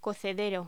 Locución: Cocedero
voz